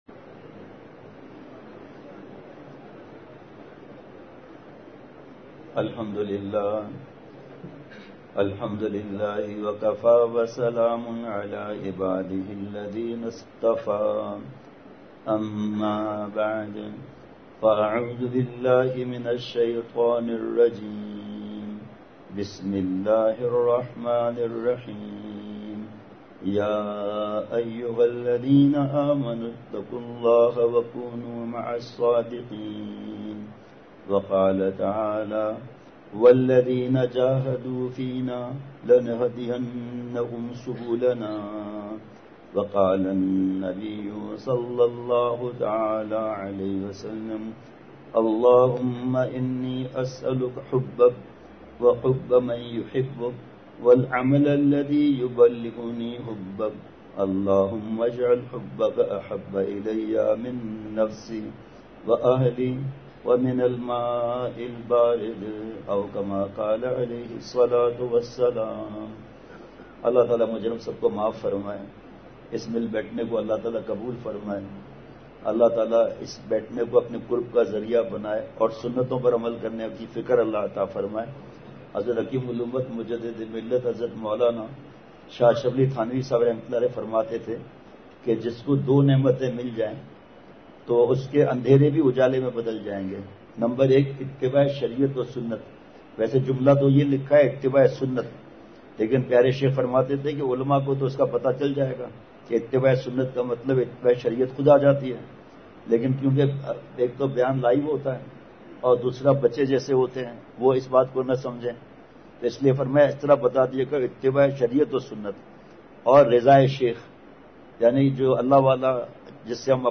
*بمقام:۔غنی انسٹیٹیوٹ آف ٹیکنیکل اینڈ اسلامک ایجوکیشن حطار واہ کینٹ *
کافی تعداد میں طلباء کا رش تھا جو یہاں دینی تعلیم حاصل کررہے ہیں بڑ حال تھا پورا طلباء سے پورا بھر گیا احباب کو اور مقامی لوگوں کو بالکل آخر میں جگہ ملی جگہ فل ہوگئی تو باہر لوگوں نے بیٹھ کر بیان سنا۔۔